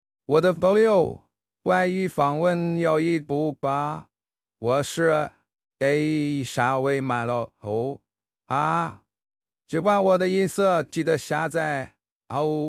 Ai《沙威玛传奇》老头 RVC模型
本训练基于RVC V2 训练，GPT版本请点击